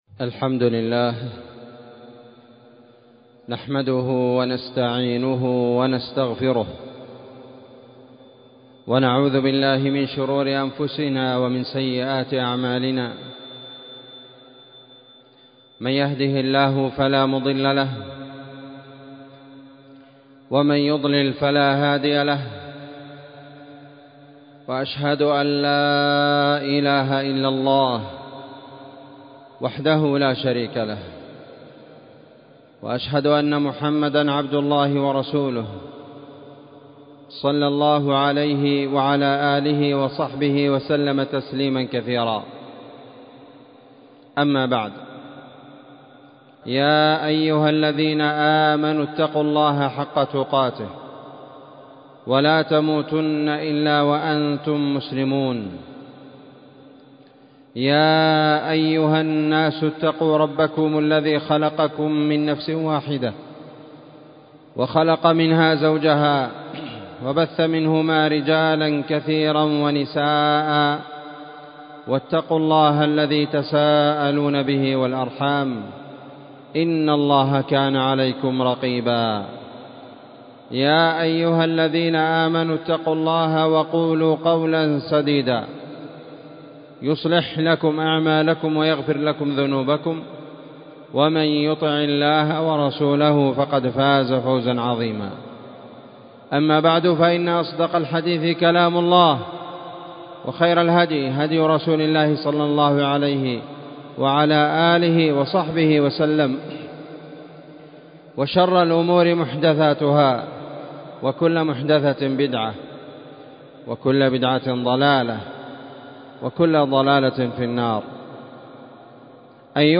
خطبة قيمة